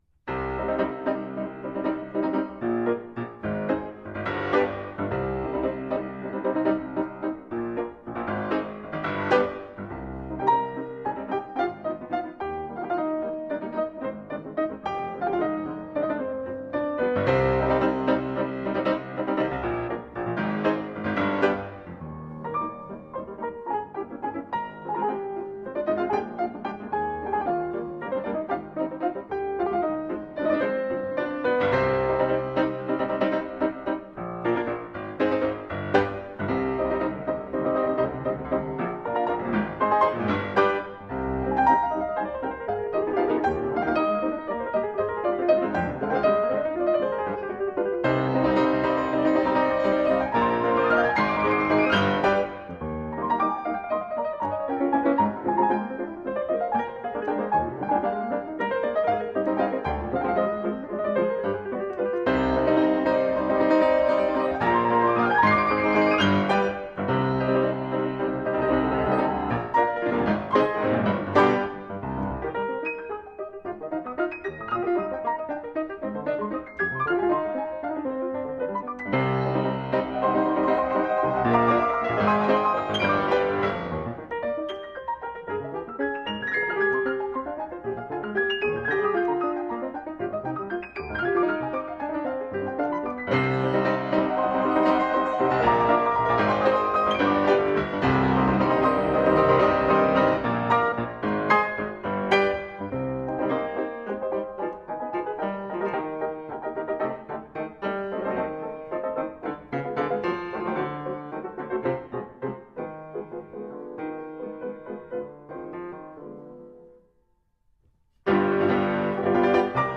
激情四射，动情舞蹈